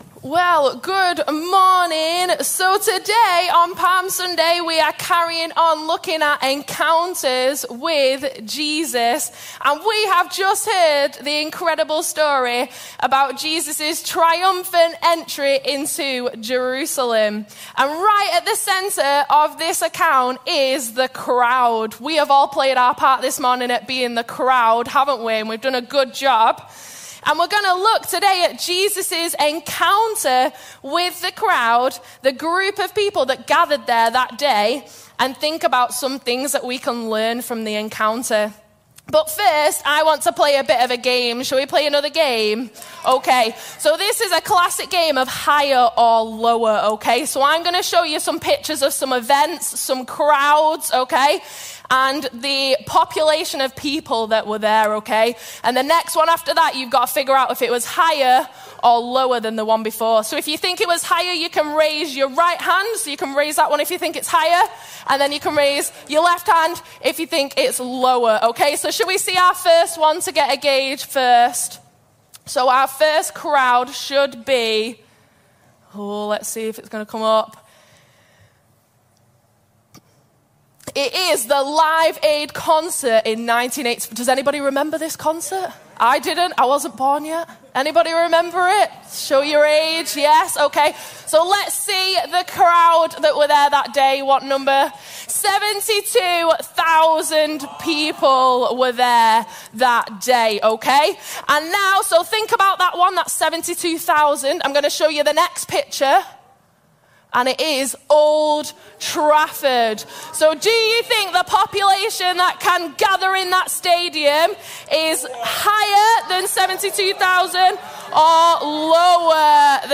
This podcast includes messages from the bridge main services.